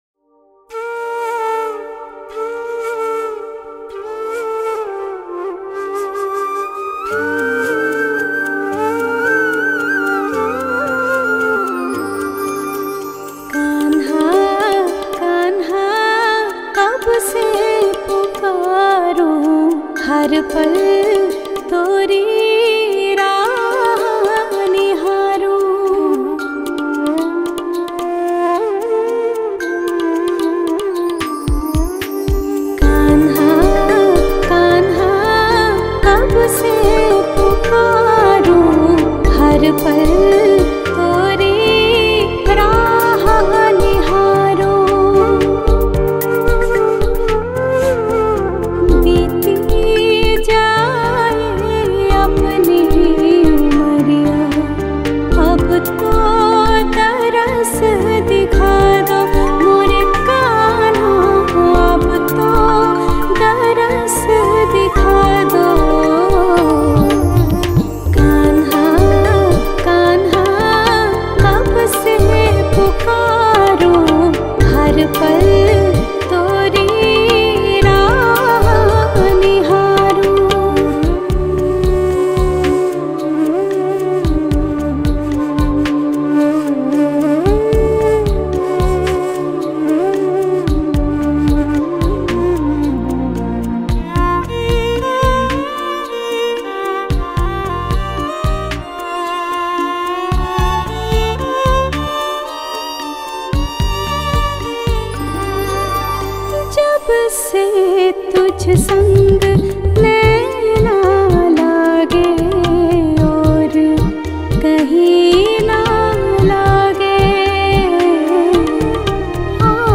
Hindi Bhakti Song